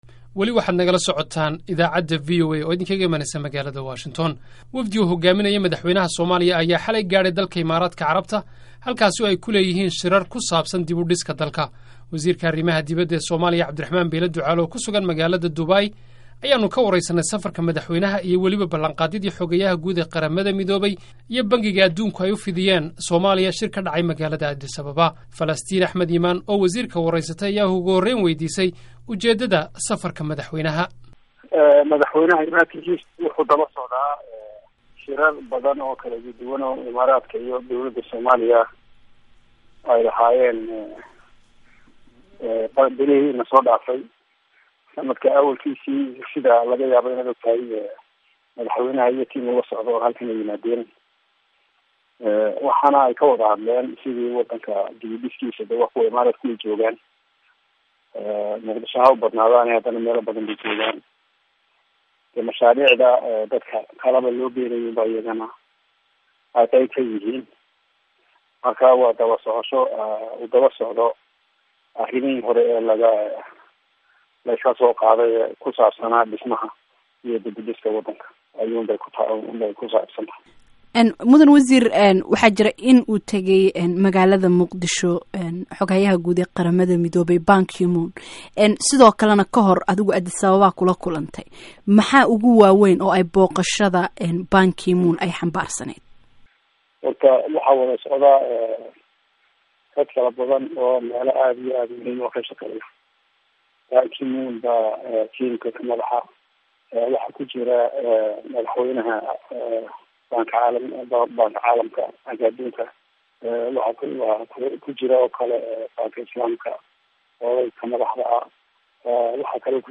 Wafdi uu hogaaminayo Madaxweynaha Soomaaliya ayaa xaley soo gaarey dalka Imaardaaka Carabta halkaasoo ay ku leeyihiin shirar ku saabsan dib u dhiska dalka. Wasiirka arrimaha dibada Somalia Abdirahman Beyle Ducaale oo ku sugan Magaalada Abu Dhabi Ayaan ka wareystey safarka madaxweynaha iyo waliba balanqaadkii xoghayaha guud ee QM iyo Bankiyada caalamiga ah ay u fidiyeen Somalia shir ka dhacay magaalada Addis Ababa.